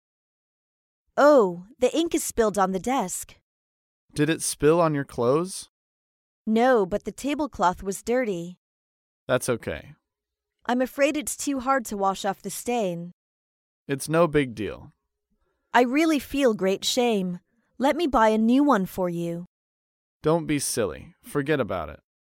在线英语听力室高频英语口语对话 第118期:弄错物品致歉(2)的听力文件下载,《高频英语口语对话》栏目包含了日常生活中经常使用的英语情景对话，是学习英语口语，能够帮助英语爱好者在听英语对话的过程中，积累英语口语习语知识，提高英语听说水平，并通过栏目中的中英文字幕和音频MP3文件，提高英语语感。